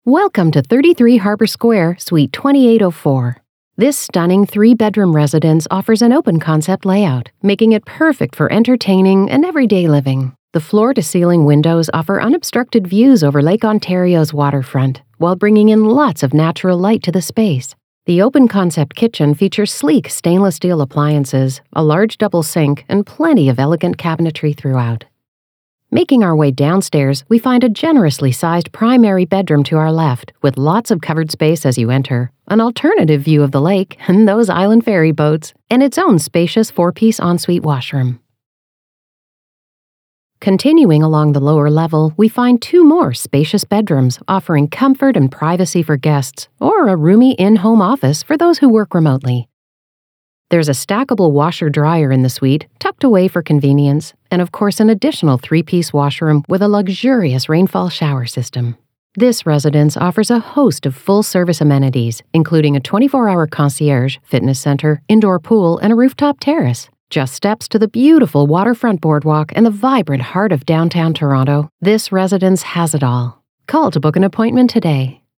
Dublador inglês (canadense)